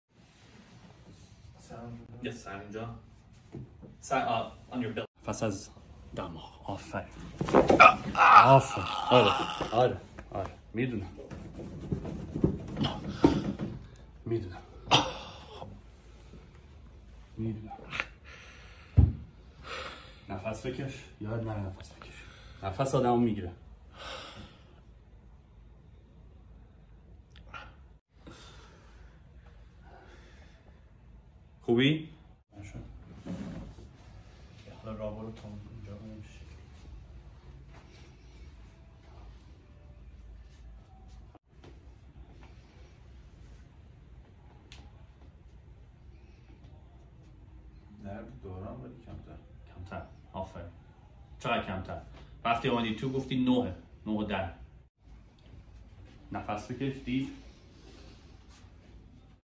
Cracks & Crunchy !